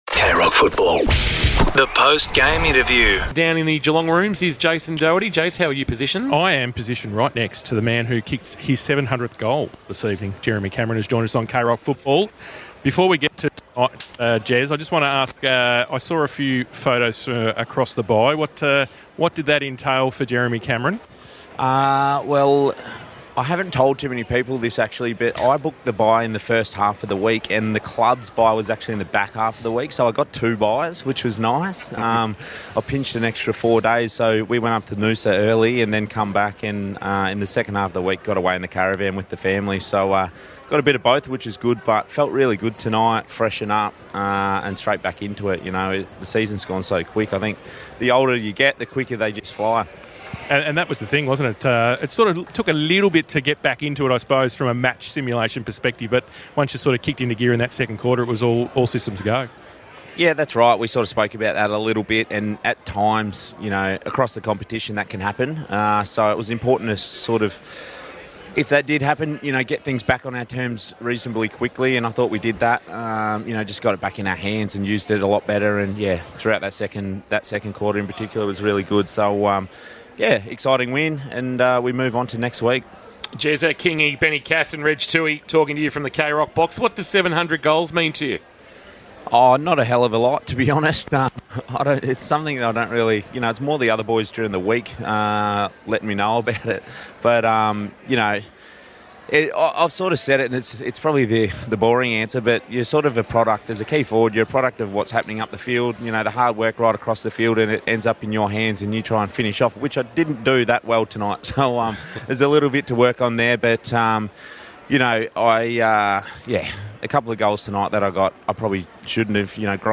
2025 - AFL - Round 17 - Geelong vs. Richmond: Post-match interview - Jeremy Cameron (Geelong Cats)